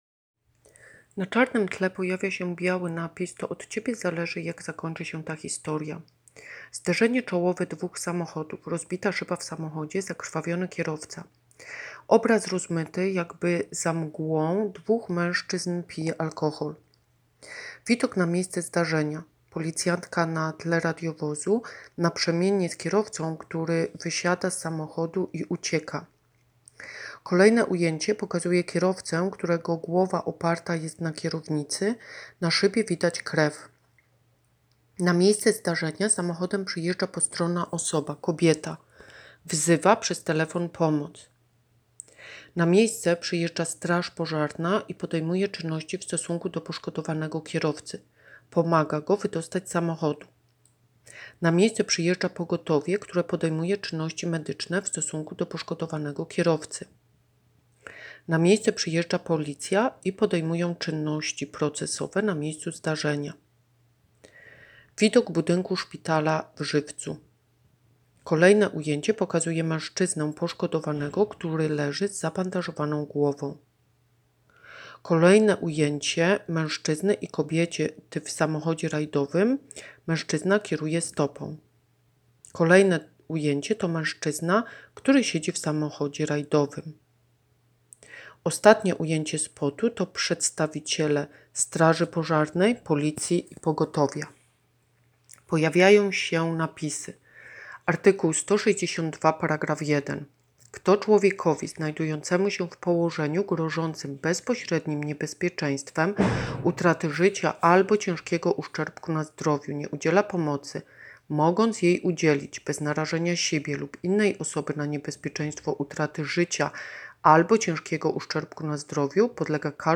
Nagranie audio Audiodeskrypcja spotu